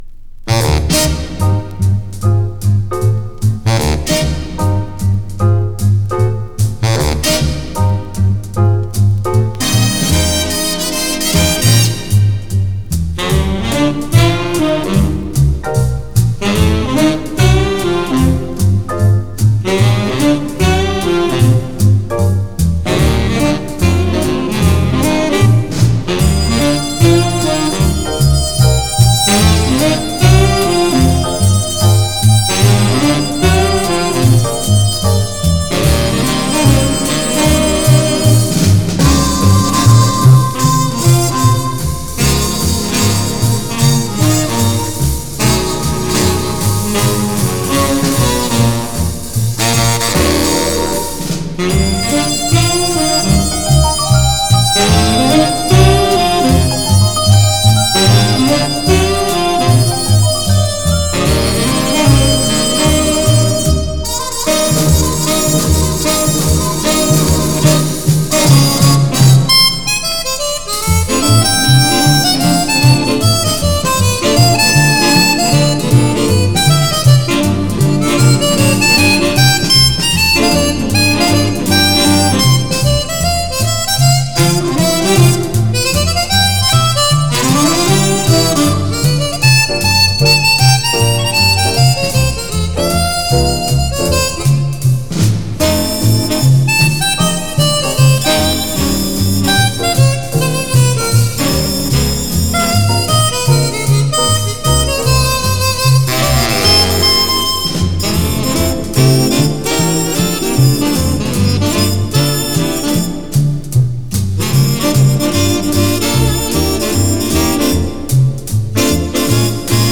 Genre: Jazz, Pop
Style: Easy Listening